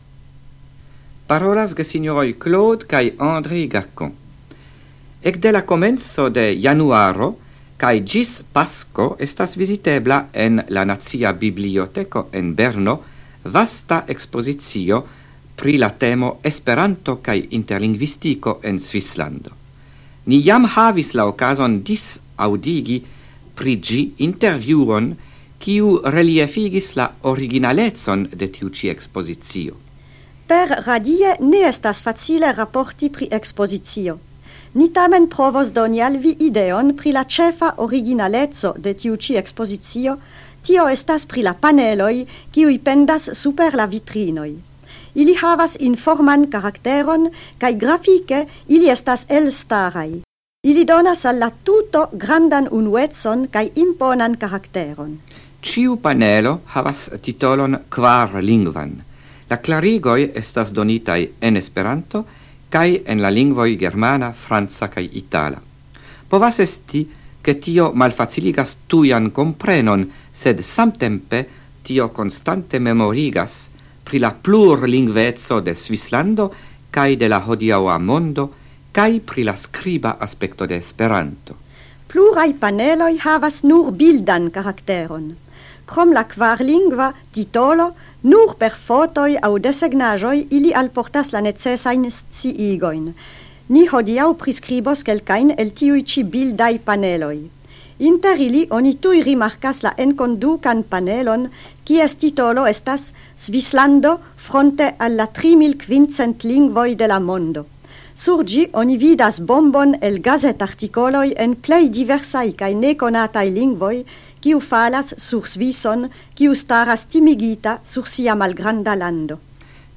Radioprelegoj en la jaro 1979